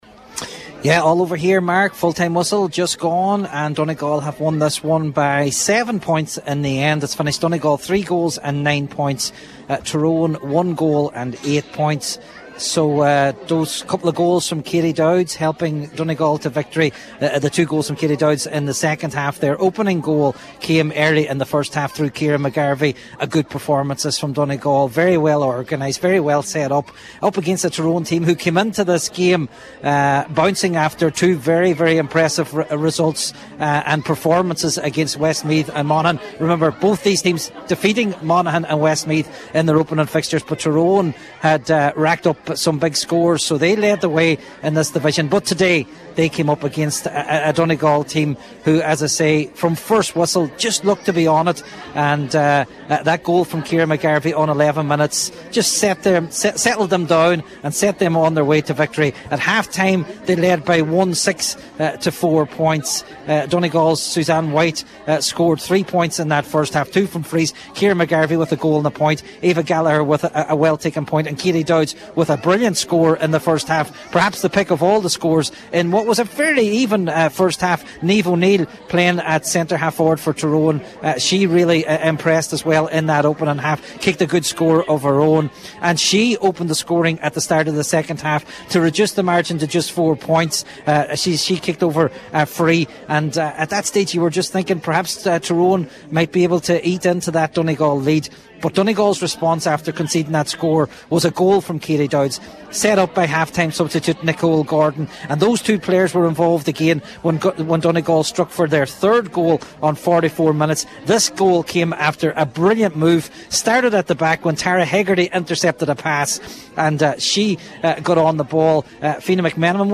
live at full time